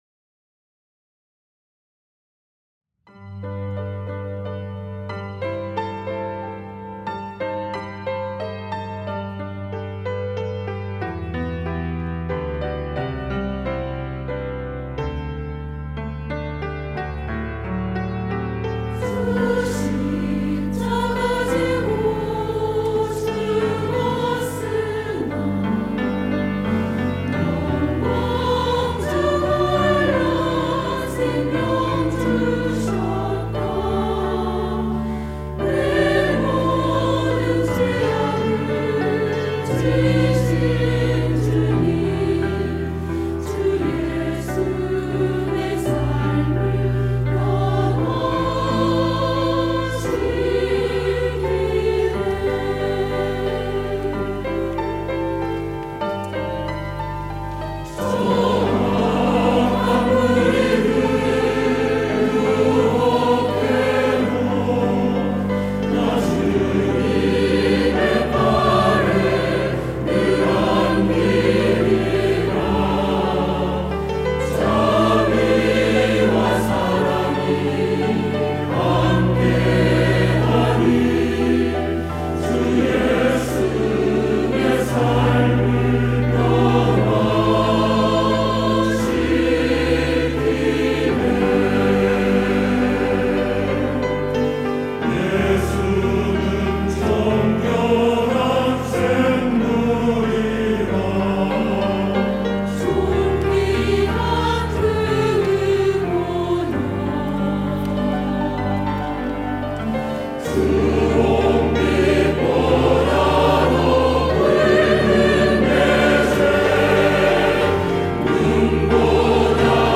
할렐루야(주일2부) - 주 예수 내 삶을 변화시키네
찬양대